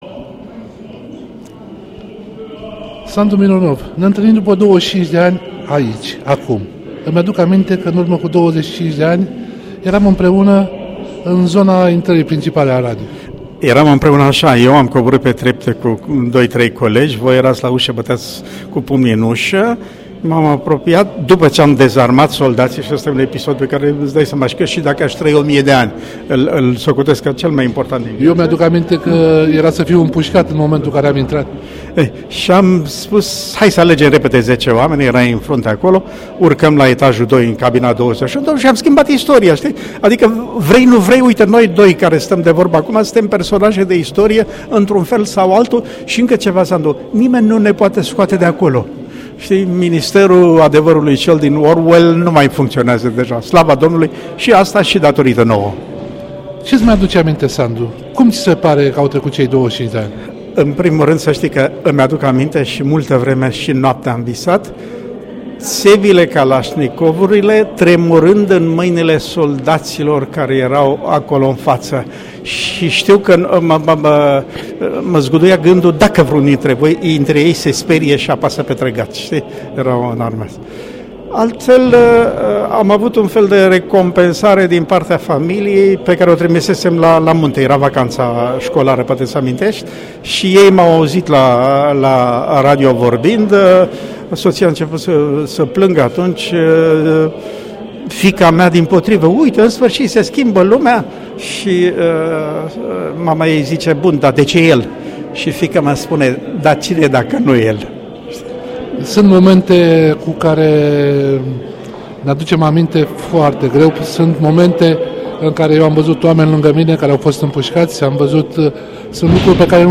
După 25 de ani, doi oameni se întâlnesc în incinta radioului si îsi aduc aminte de ce a fost atunci.